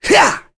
Baudouin-Vox_Attack2.wav